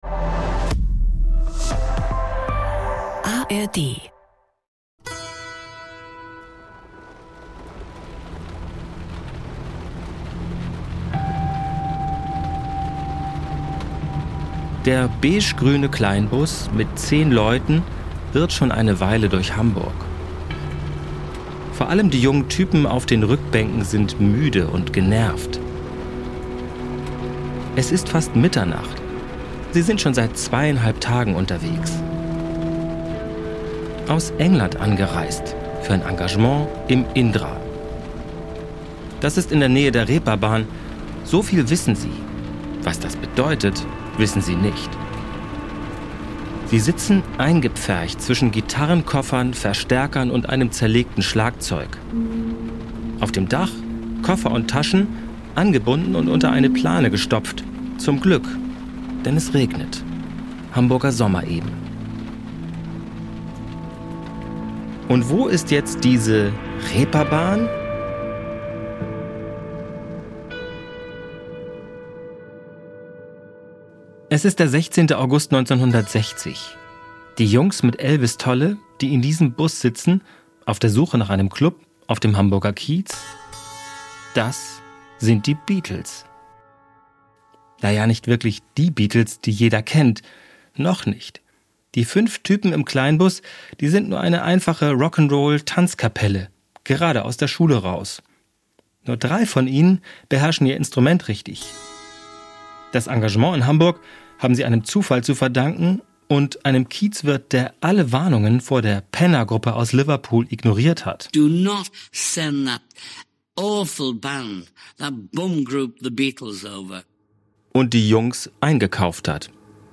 Ein Podcast mit viel Musik, O-Tönen von Zeitzeugen und seltenem Archivmaterial.